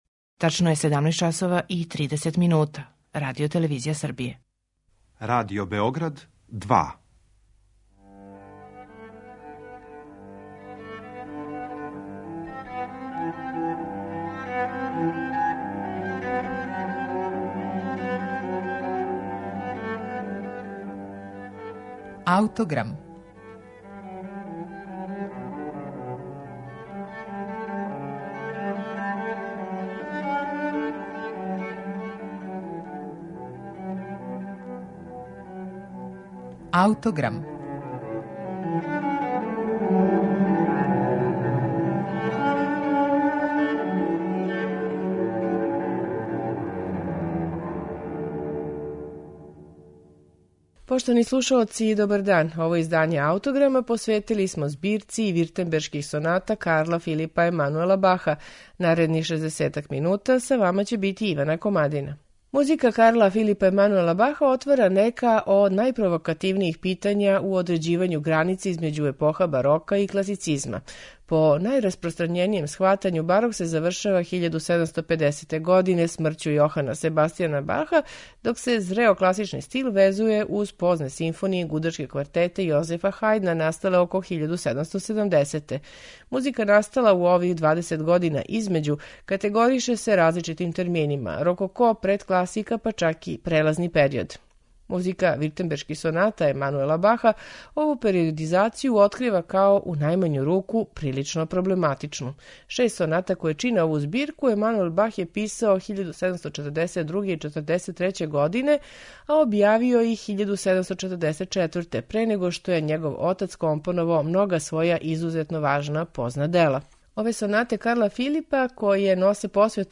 писане су за чембало
У данашњем Аутограму, Виртембершке сонате Карла Филипа Емануела Баха слушаћете у интерпретацији чембалисте Махана Есфаханија.